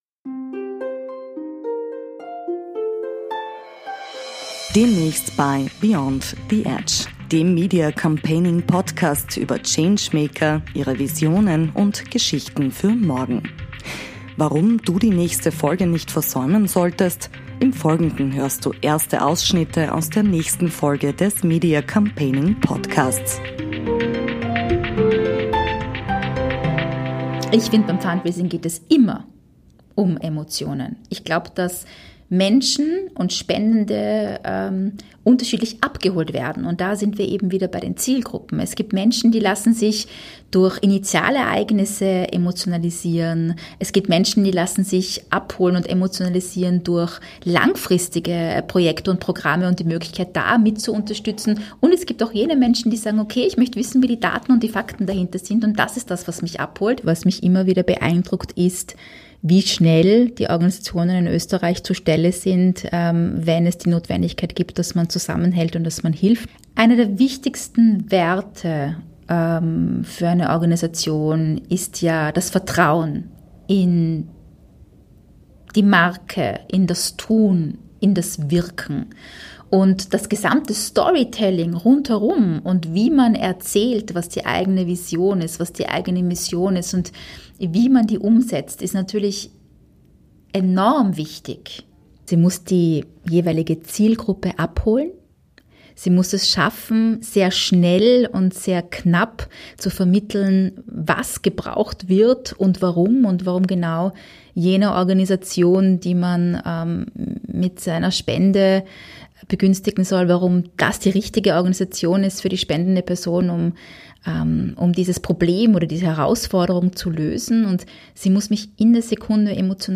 Über die Macht der Emotionen im Fundraising - Teaser